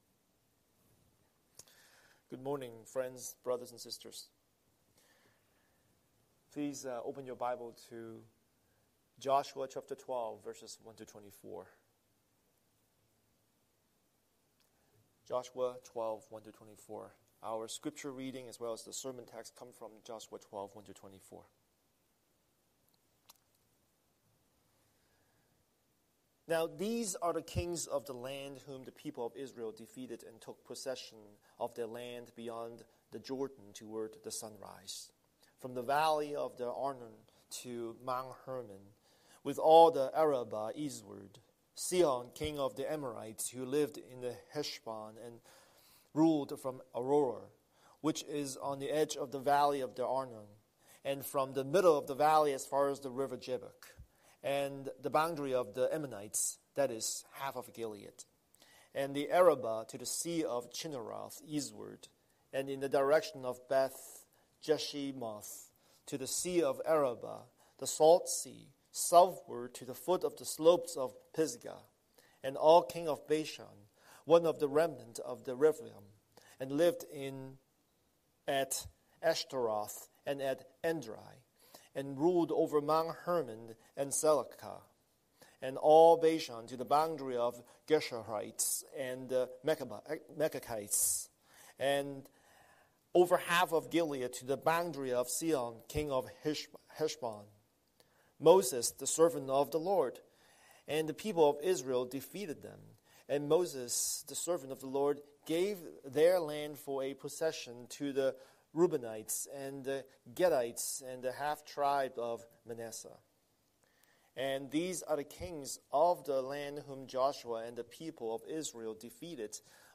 Scripture: Joshua 12:1-24 Series: Sunday Sermon